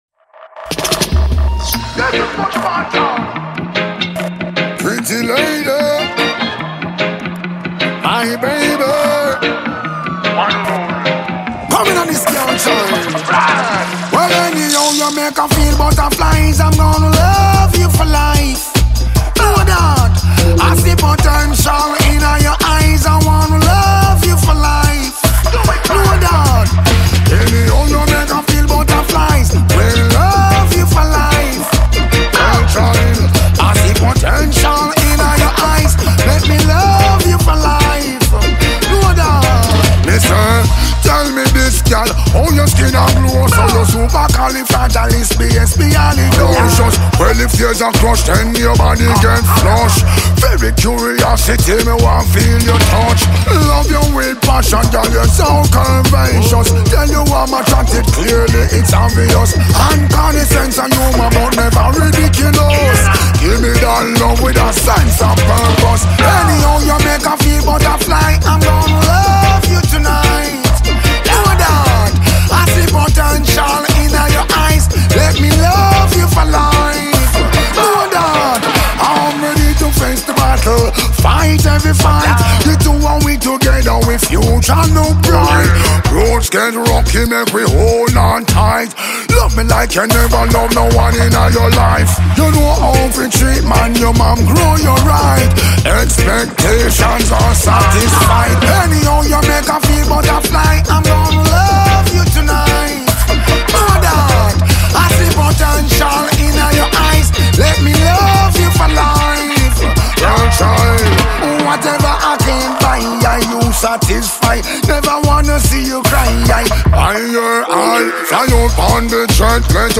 smooth vocals
rich production